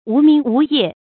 無明無夜 注音： ㄨˊ ㄇㄧㄥˊ ㄨˊ ㄧㄜˋ 讀音讀法： 意思解釋： 猶言不分晝夜。